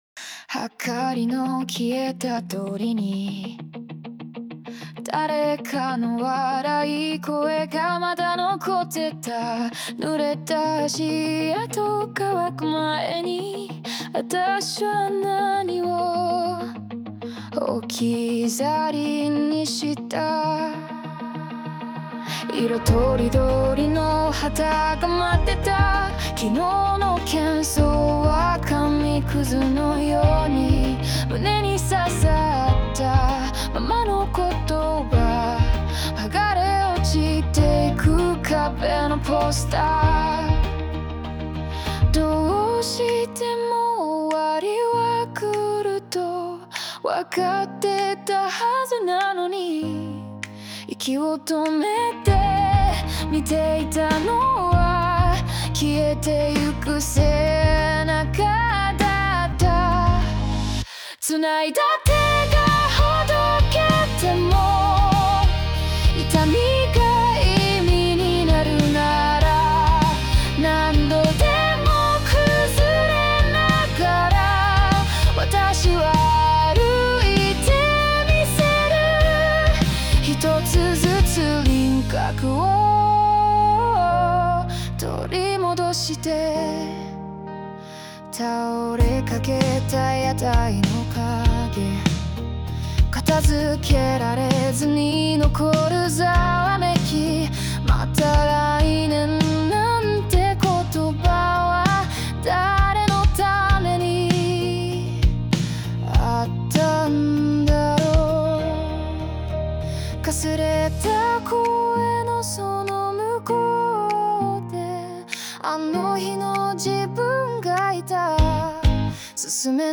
邦楽女性ボーカル著作権フリーBGM ボーカル
女性ボーカル邦楽邦楽 女性ボーカル入場・再入場ポップスロック切ないノスタルジック幻想的
女性ボーカル（邦楽・日本語）曲です。